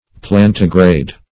Plantigrade \Plan"ti*grade\, n. (Zool.)